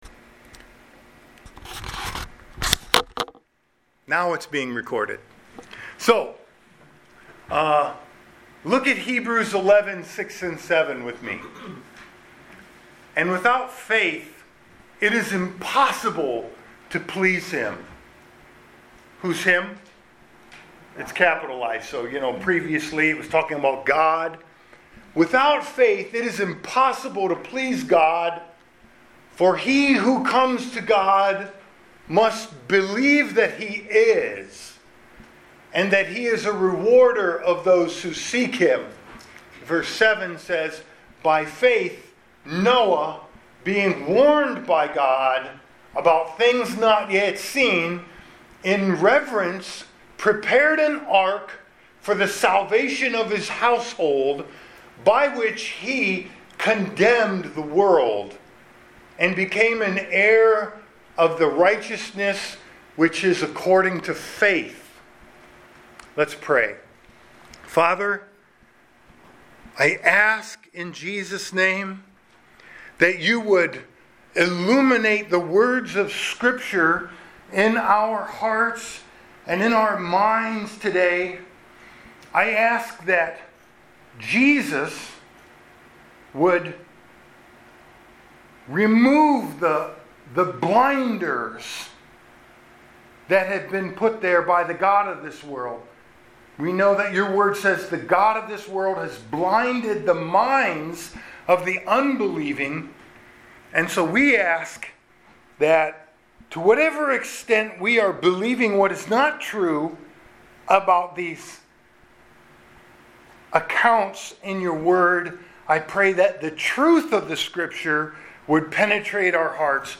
Gethsemane Covenant Church Archived Sermons